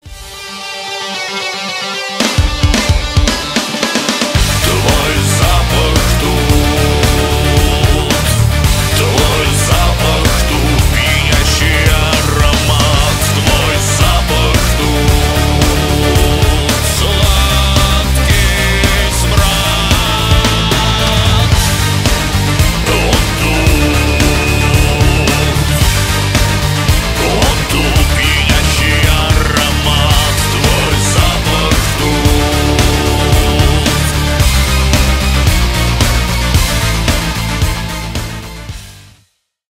Рок Металл
громкие
кавер